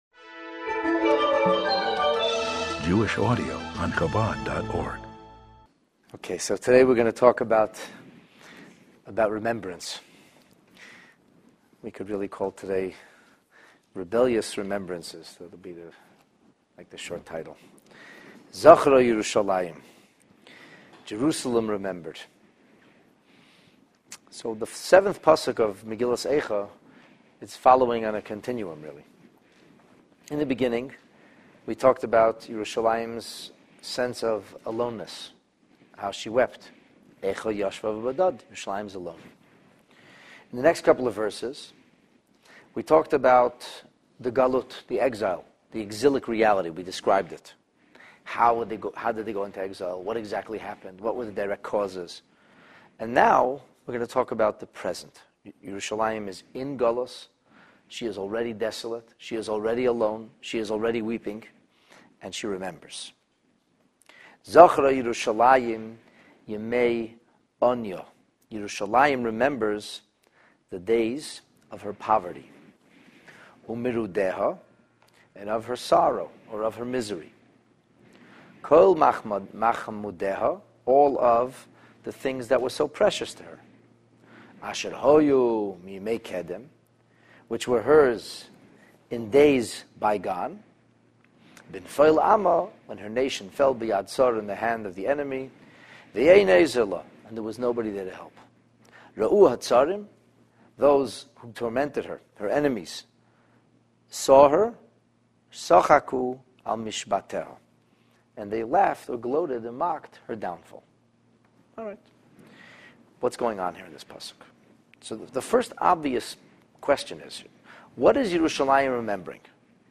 This text-based class on Megillat Eicha focuses on verse 7 of the first chapter.